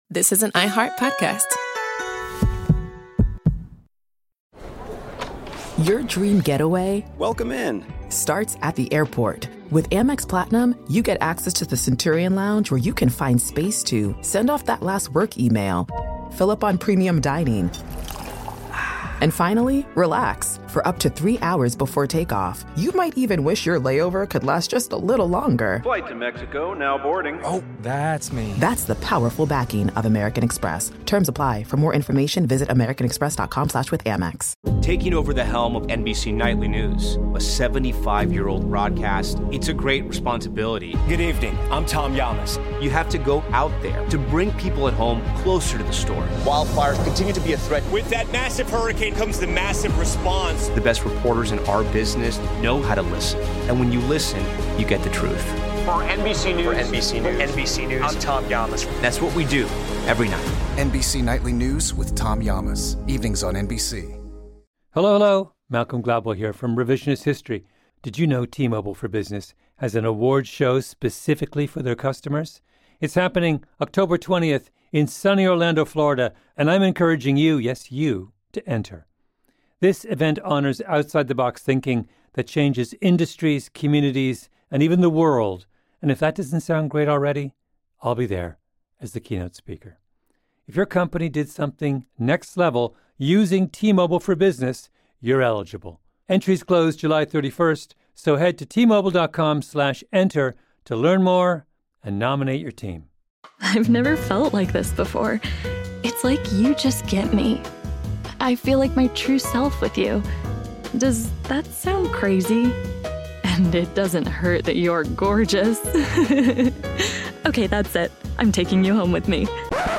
During his 2016 Pro Football Hall of Fame induction speech, he shared the story of that unforgettable tribute to his dad.